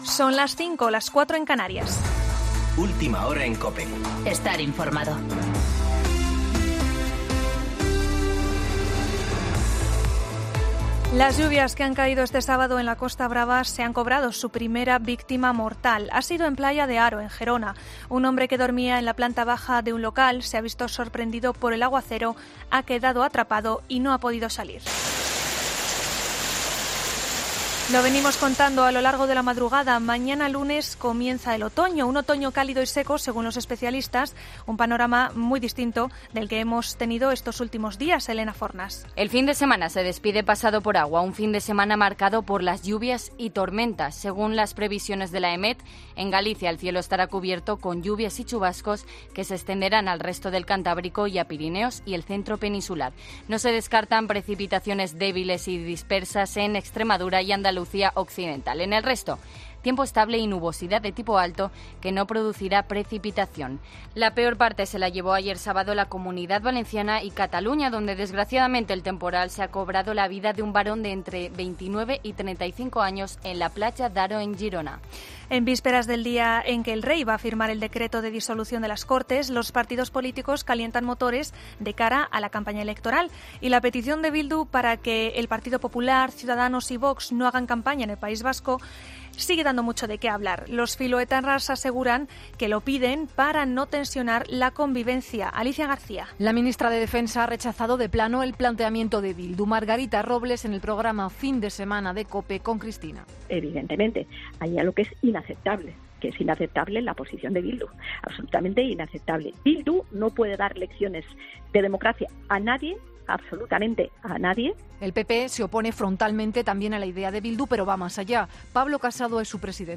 Boletín de noticias COPE del 22 de septiembre a las 05.00